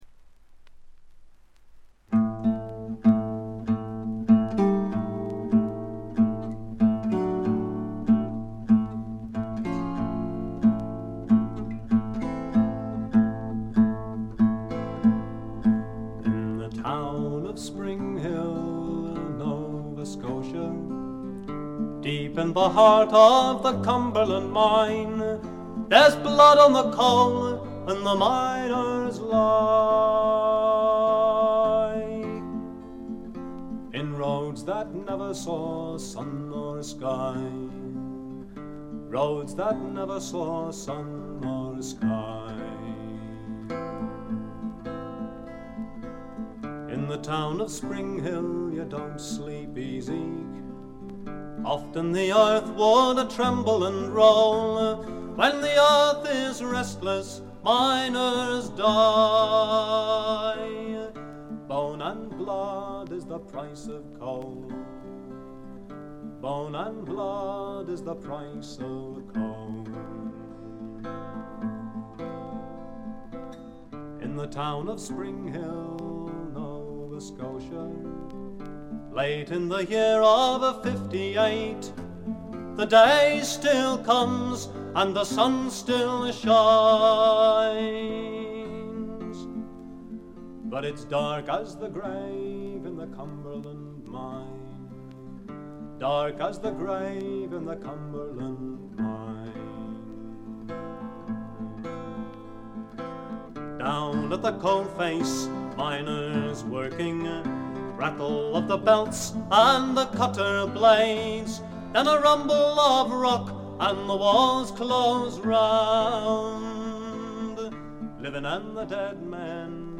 ところどころで軽微なチリプチ。目立つノイズはありません。
英国フォーク必聴盤。
Stereo盤。
試聴曲は現品からの取り込み音源です。